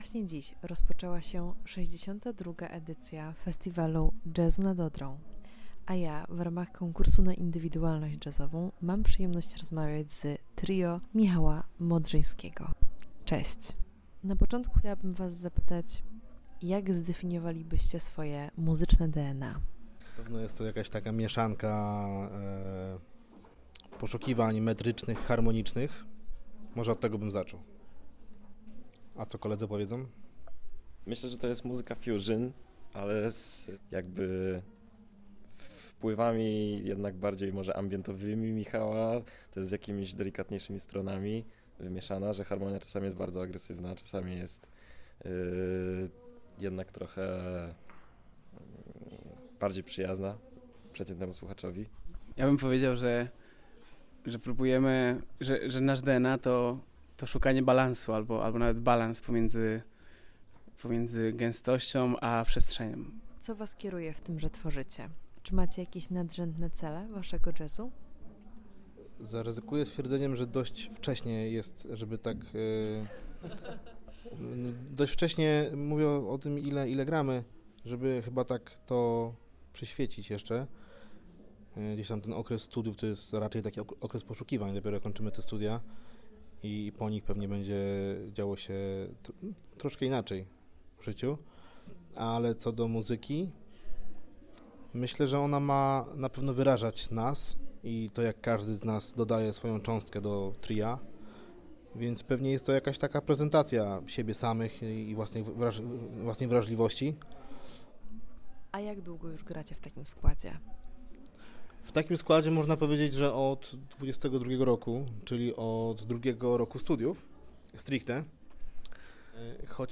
instrumenty klawiszowe
gitara basowa
perkusja